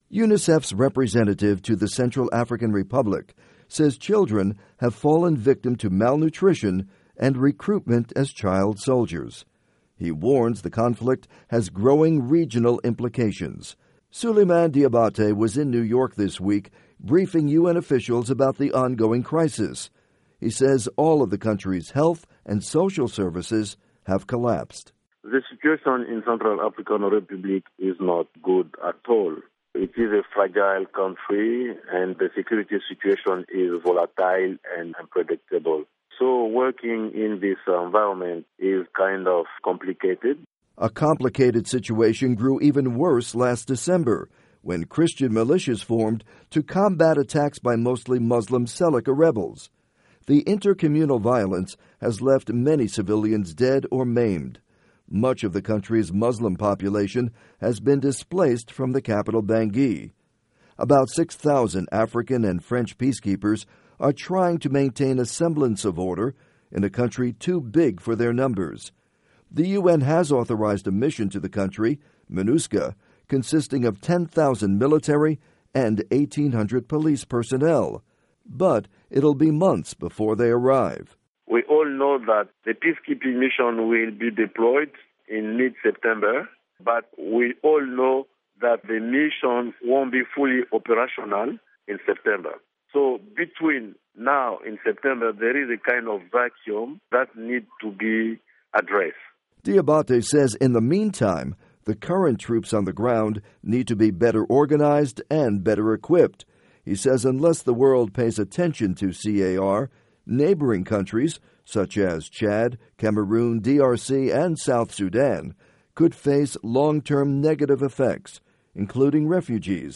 report on UNICEF in CAR